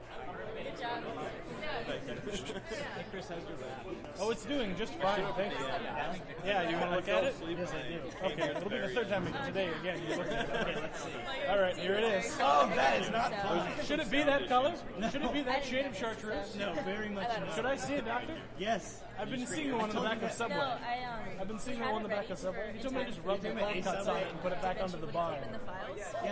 杂项的声音 " 群众瓦拉中距离
描述：从中等角度看人群沃拉
Tag: 语音 透视 谈话 人群 瓦拉